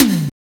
R BAMBTOMHI.wav